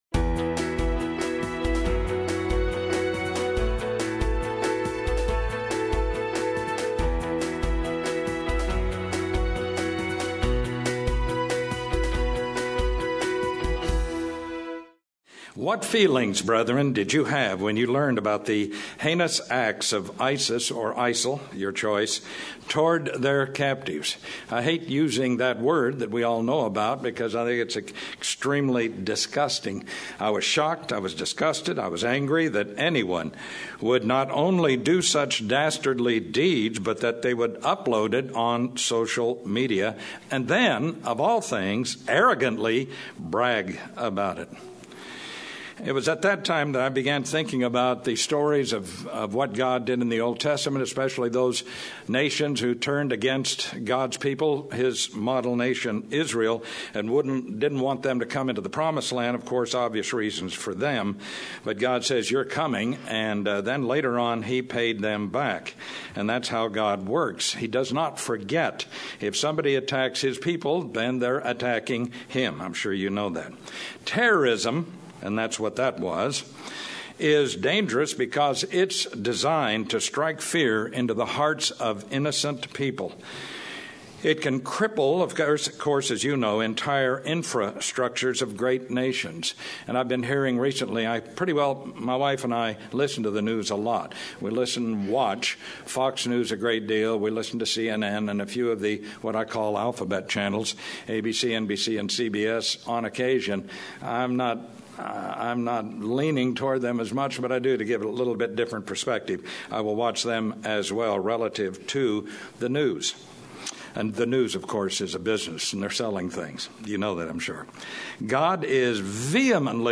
This sermon explores the history and prophecy of terrorism and how knowledge of God's Word builds faith that can overcome the fear associated with terrorism.